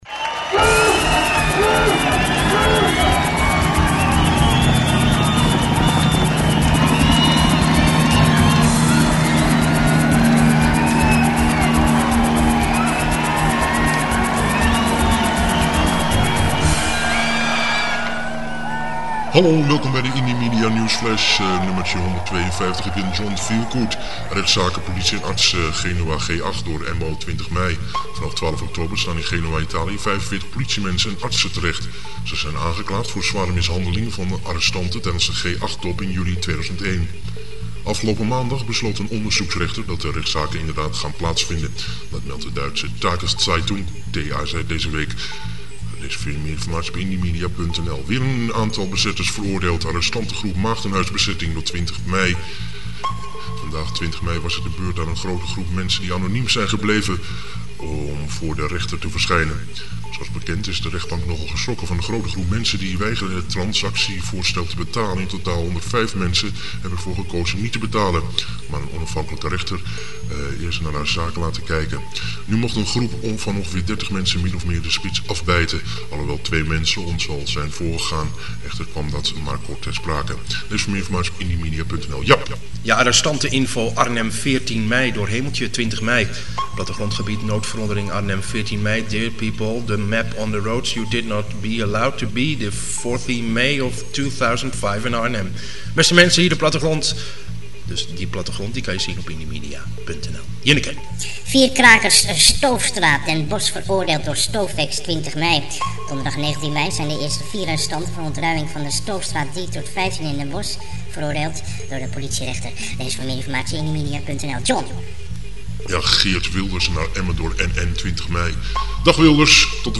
De audionewsflash van indymedia punt nl met o.a:Rechtszaken politie & artsen Genua (G8 ),Weer een aantal bezetters veroordeeld!! en nog veel meer met het weer van onze weerman.